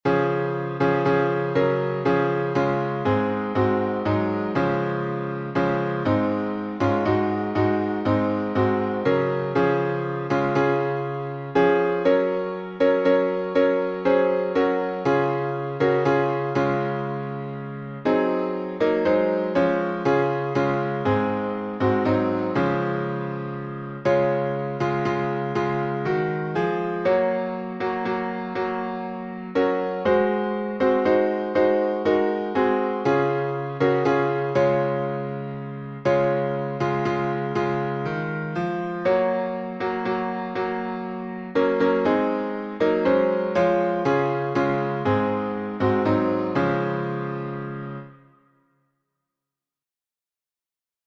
#2045: Saved by the Blood — D flat, three stanzas | Mobile Hymns